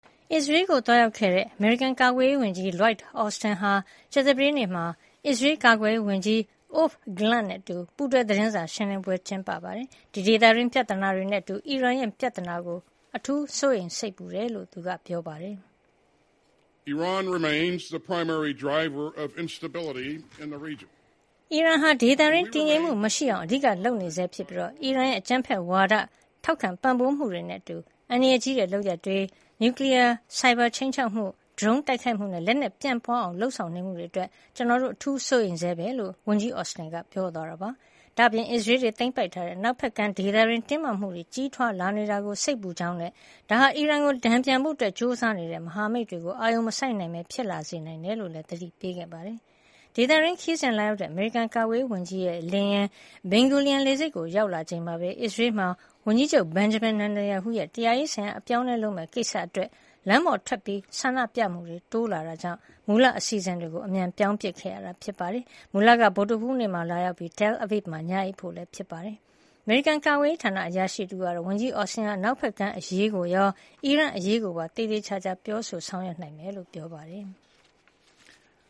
အစ္စရေးကို သွားရောက်ခဲ့တဲ့ အမေရိကန် ကာကွယ်ရေးဝန်ကြီး Lloyd Austin က ကြာသပတေးနေ့ အစ္စရေး ကာကွယ်ရေးဝန်ကြီး Yoav Galant နဲ့အတူ ပူးတွဲ သတင်းစာရှင်းလင်းပွဲ ကျင်းပရာမှာ ဒေသတွင်းပြဿနာတွေနဲ့ အတူ အီရန်ပြဿနာကို စိုးရိမ်စိတ်ပူမိကြောင်း ပြောကြားခဲ့ပါတယ်။